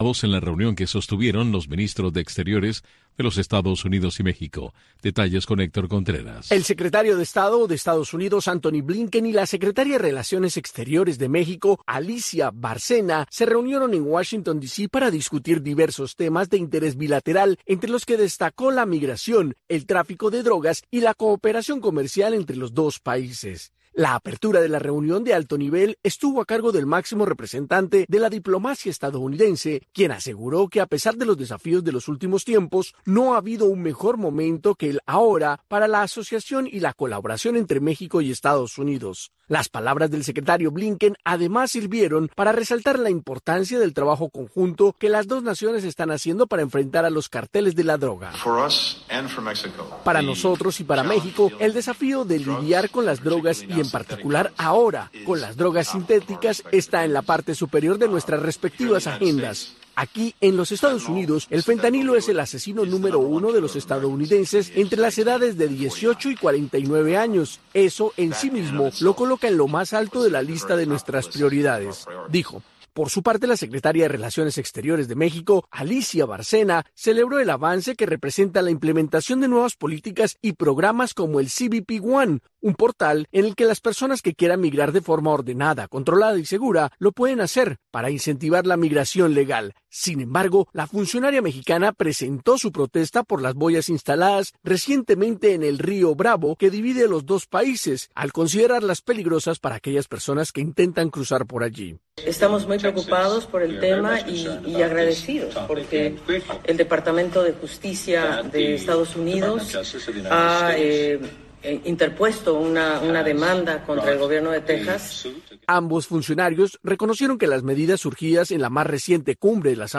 Un espacio donde se respeta la libertad de expresión de los panelistas y estructurado para que el oyente llegue a su propia conclusión.